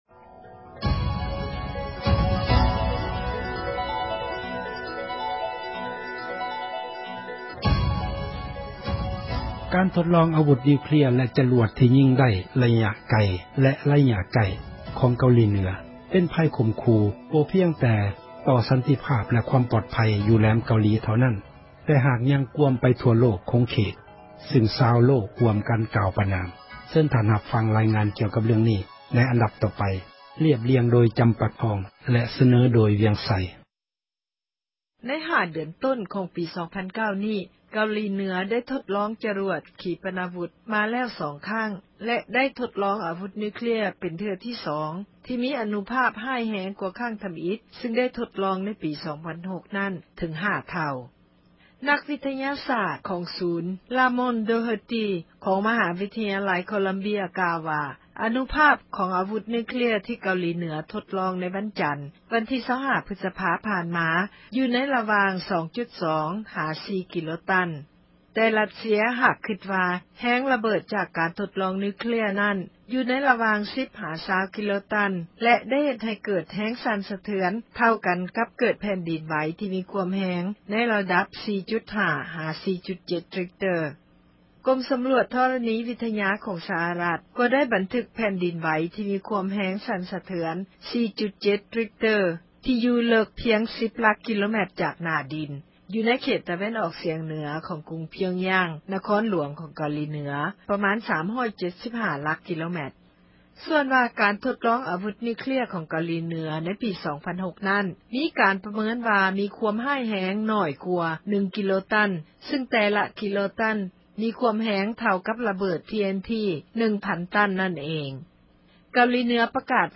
ການທົດລອງ ອາວຸດນິວເຄລັຽ ແລະຈະຣວດ ທີ່ຍິງໄດ້ ໄລຍະໄກ ແລະໄລຍະໄກ້ ຂອງ ເກົາຫລີເໜືອ ເປັນໄພຂົ່ມຂູ່ ບໍ່ພຽງແຕ່ ຕໍ່ ສັນຕິພາບ ແລະຄວາມປອດໄພ ຢູ່ແຫລມເກົາຫລີ ເທົ່ານັ້ນ ແຕ່ຫາກຍັງກວມ ໄປທົ່ວທຸກ ໂຂງເຂດ ຊຶ່ງຊາວໂລກ ຮ່ວມກັນ ກ່າວປະນາມ. ເຊີນທ່ານຮັບຟັງ ລາຍງານກ່ຽວກັບ ເລື້ອງນີ້ໄດ້ ໃນອັນດັບ ຕໍ່ໄປ....